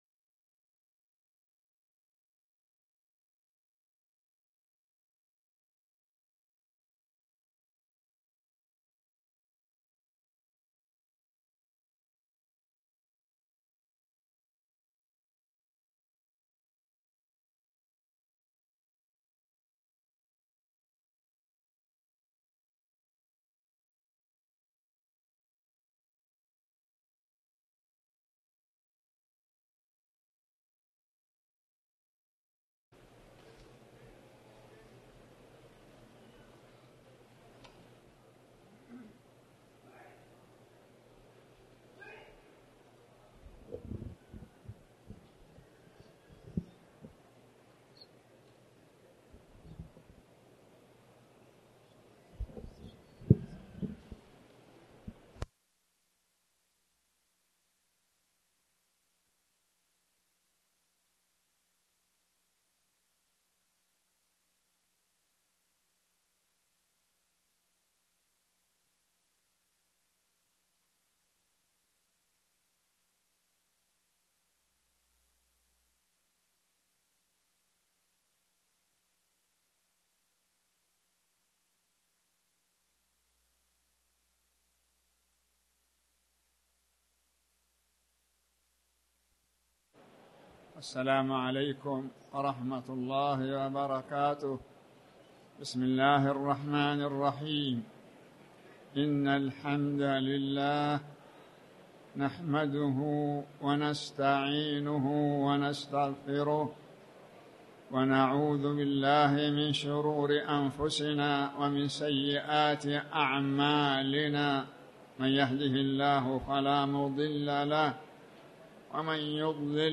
تاريخ النشر ١١ رجب ١٤٣٩ هـ المكان: المسجد الحرام الشيخ